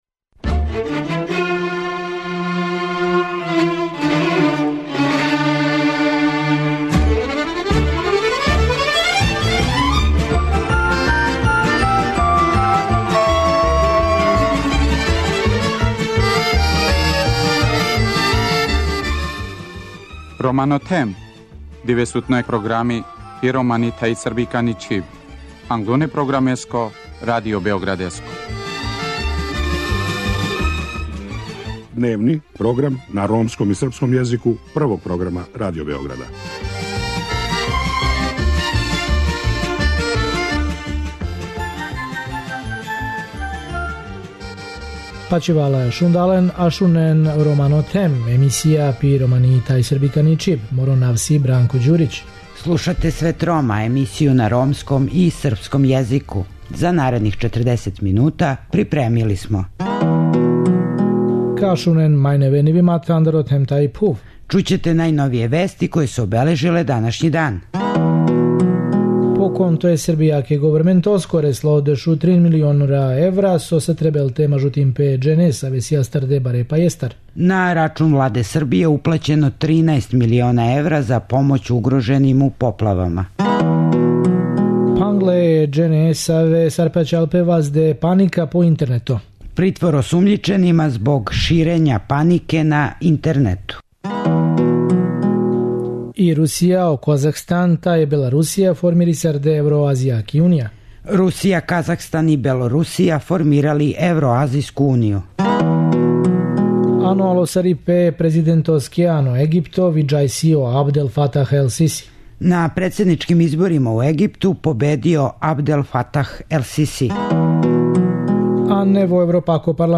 Гошћа Света Рома је Славица Денић, помоћница покрајинског секретара за привреду, запошљавање и равноправност полова из Сектора за унапређење положаја Рома, која говори о напорима Владе Војводине да унапреди интеграцију Рома у већинско друштво.